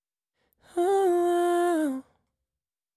Ad-libs – 80BPM – 04
Unison-Ad-libs-80bpm-04-G-Minor-B-Major.mp3